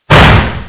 New Backfire Sounds Replacing Dump-Valve Sounds
i've attached preview mp3s along with it but make sure you turn your speakers down before opening them because they are VERY LOUD. for some reason it's not as loud in lfs.
Maybe try finding some less *sharp and agressive/loud* backfire noises?